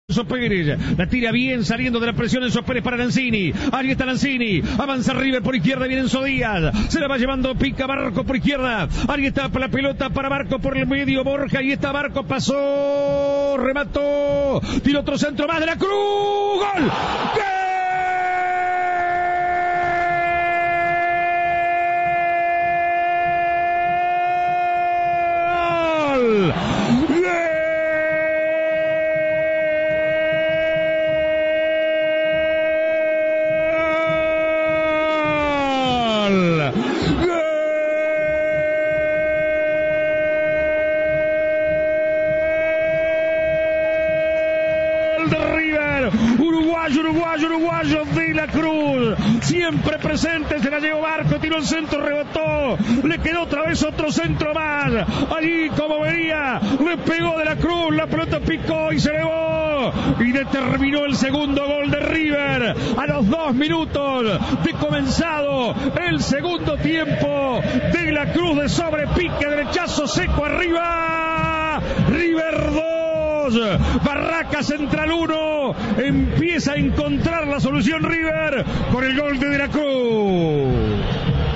Volvé a vibrar con los gritos "millonarios" en el triunfo ante Barracas